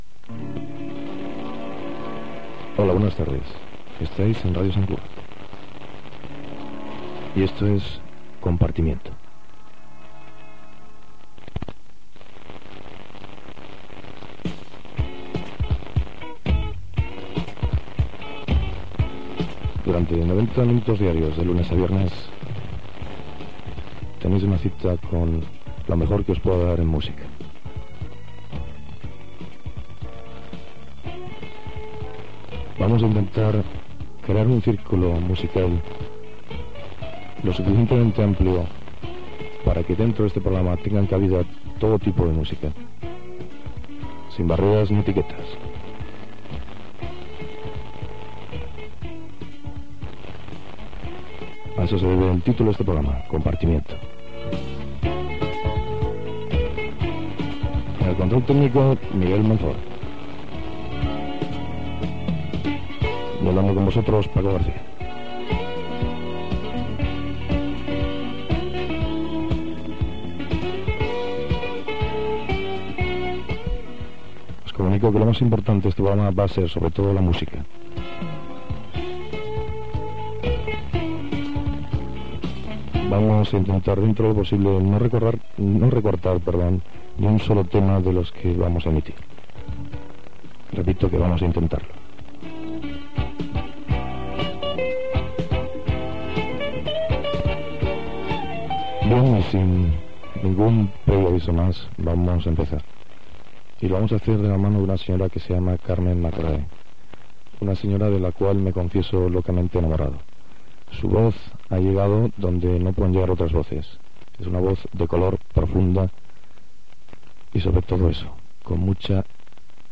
Identificació i inici del programa
Musical
FM